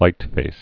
(lītfās)